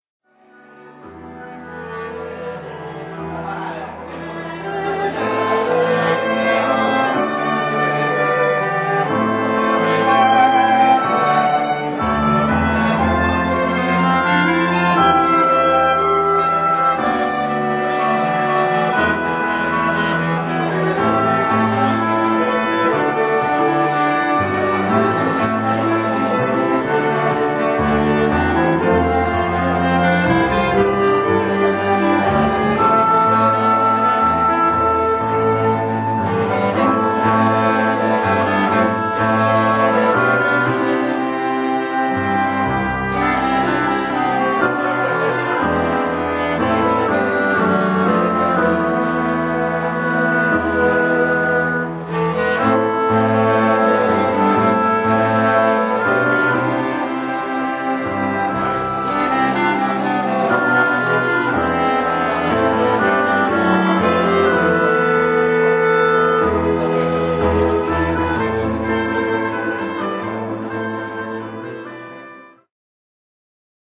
Flute、Oboe、Clarinet、Violin、Cello、Piano